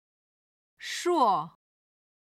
81位　硕　(shuò)　碩　ショウ